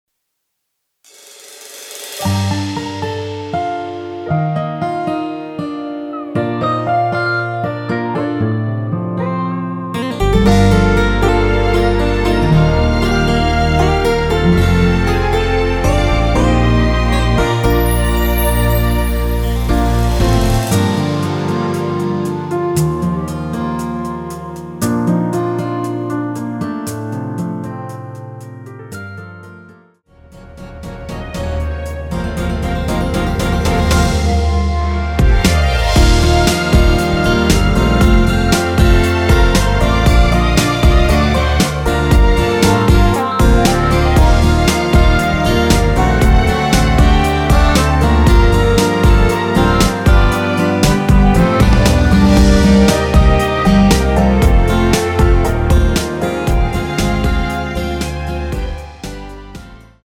원키에서(-2)내린 멜로디 포함된 MR입니다.(미리듣기 참조)
Gm
앞부분30초, 뒷부분30초씩 편집해서 올려 드리고 있습니다.
중간에 음이 끈어지고 다시 나오는 이유는